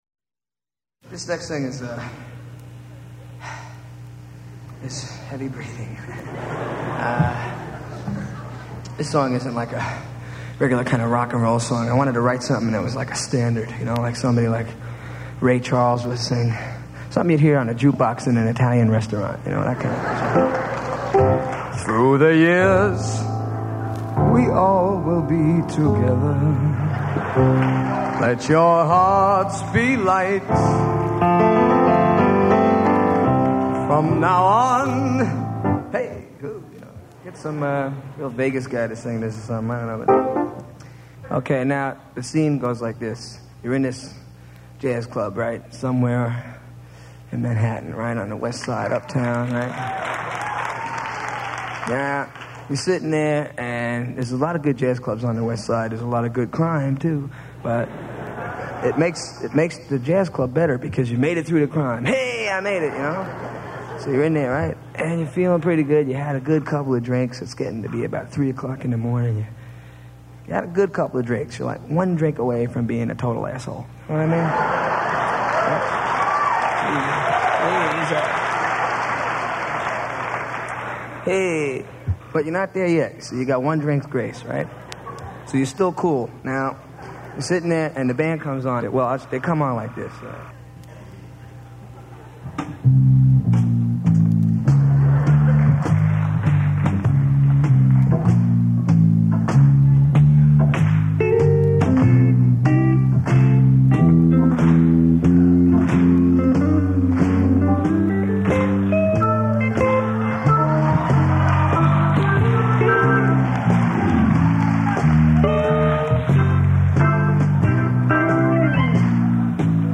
a terrific live entertainer with a superb band